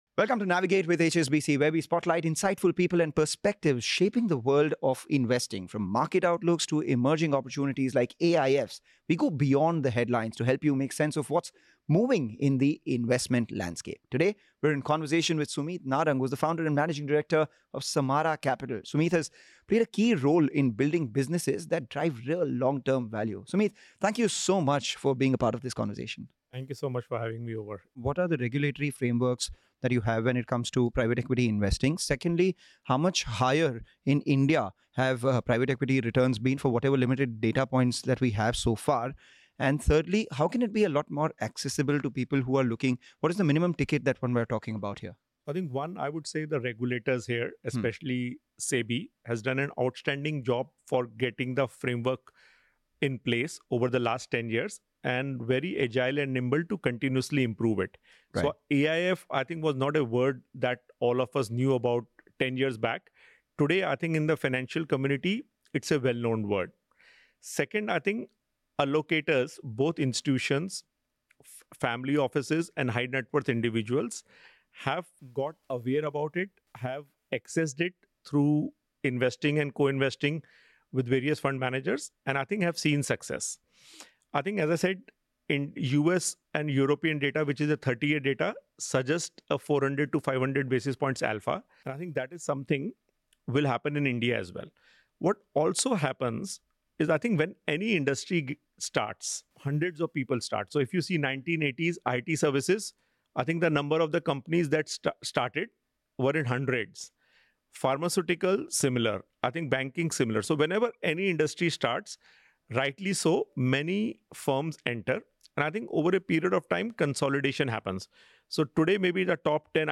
In this insightful conversation, you'll gain valuable perspectives on India's investment climate, including how shifts in demographics, rising consumer demand, digital transformation, and policy reforms are shaping the country's economic future.